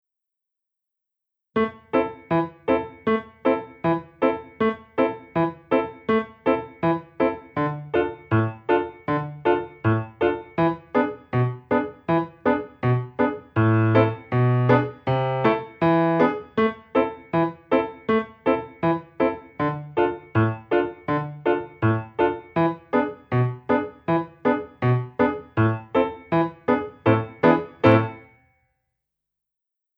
Besetzung: Violine